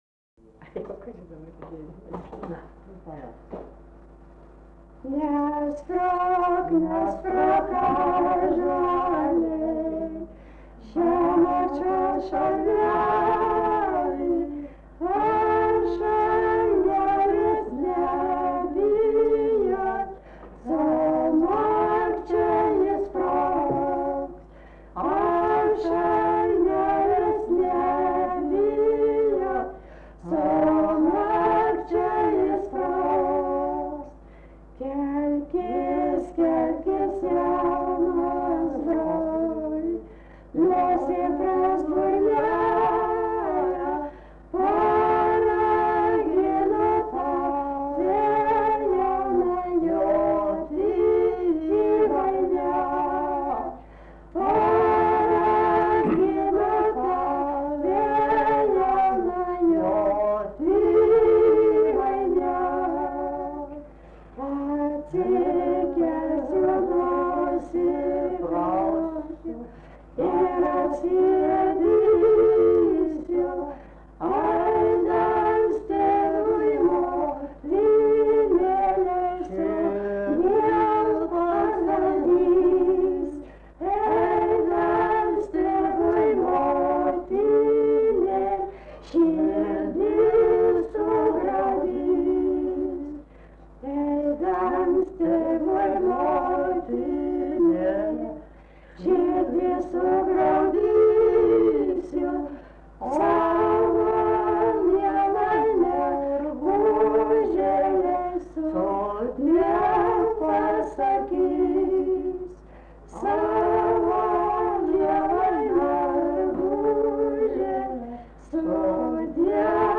vokalinis
daina